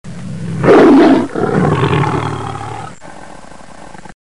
狮子 | 健康成长
lion-sound.mp3